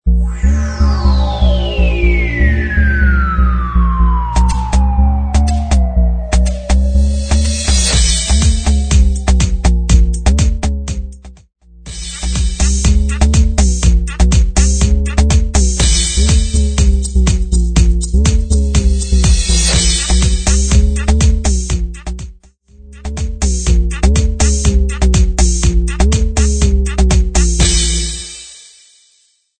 Gqom
South African